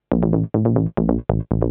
SNTHBASS036_DANCE_140_A_SC3.wav